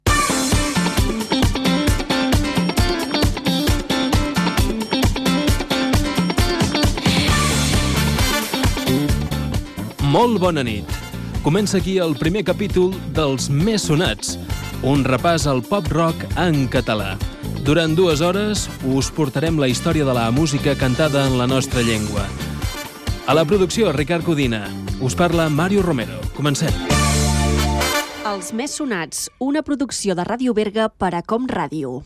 Inici del programa, equip i indicatiu
Musical